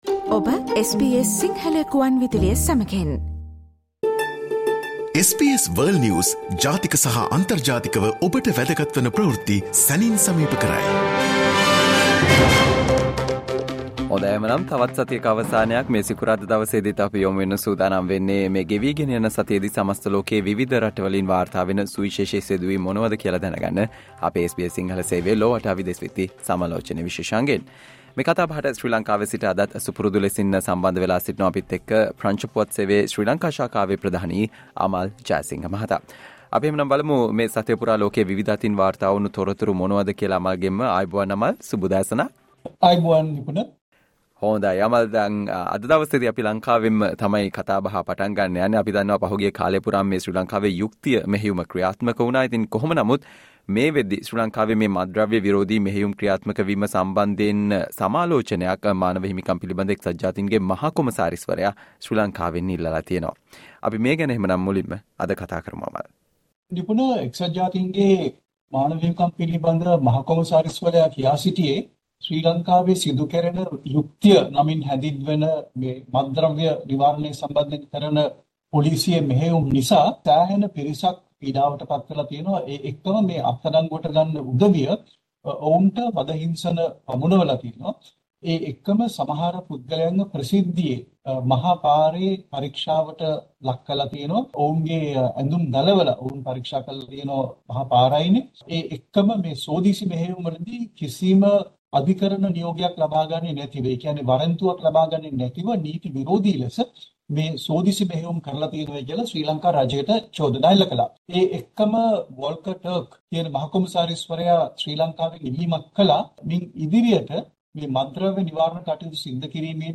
The death toll in Gaza exceeds 24,000 while Iran-Pakistan conflict heats up: World News Wrap